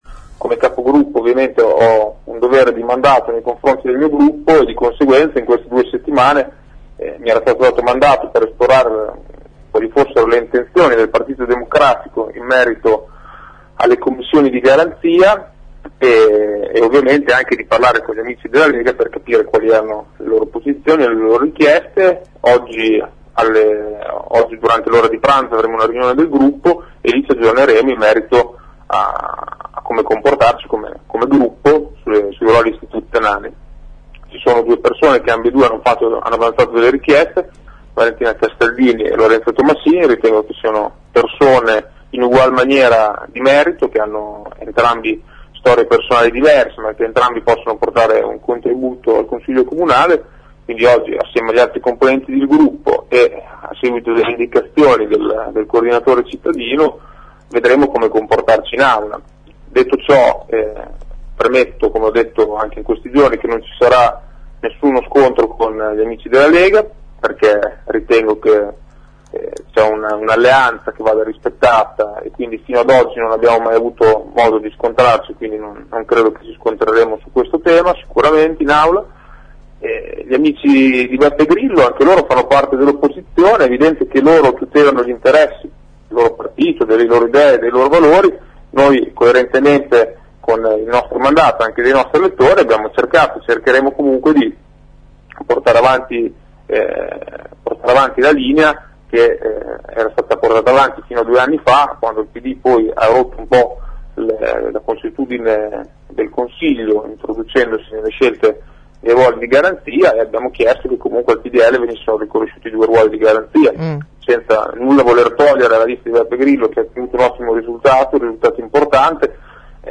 Il Pdl però rivendica la guida di entrambe come spiega il capogruppo Marco Lisei, ospite ad AngoloB